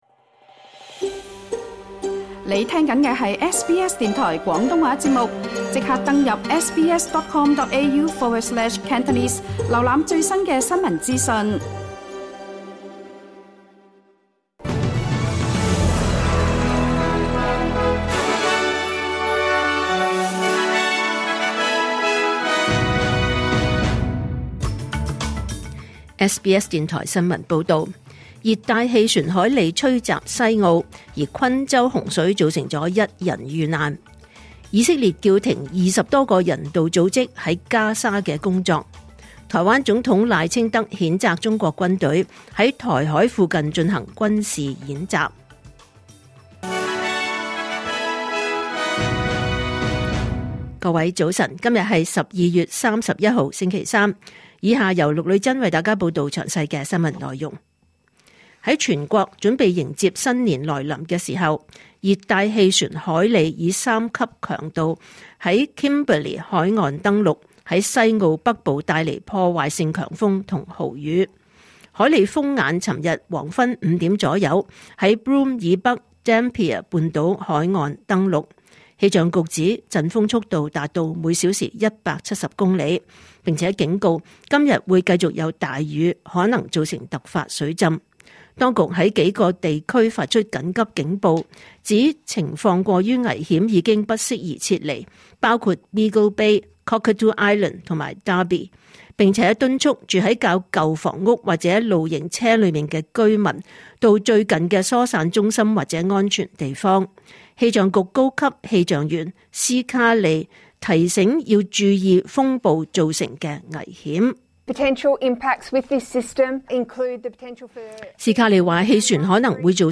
2025年12月31日SBS廣東話節目九點半新聞報道。